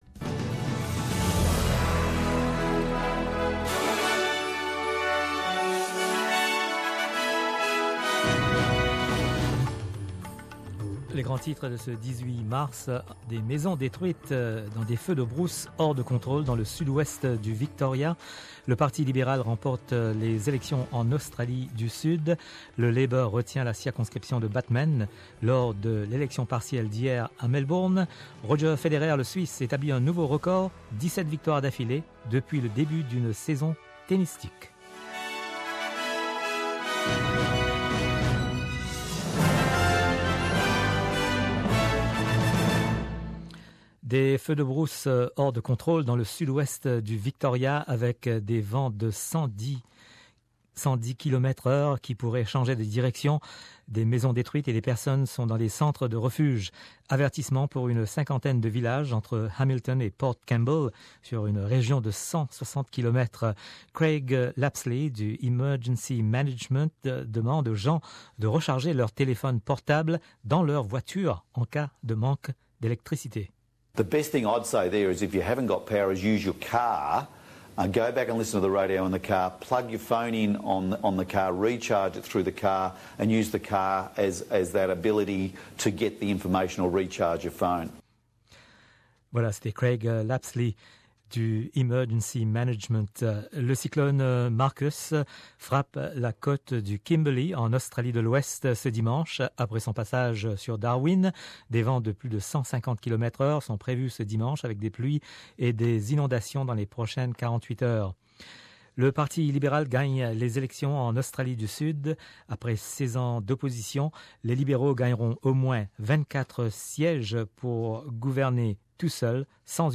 SBS French - Journal du 18/03/2018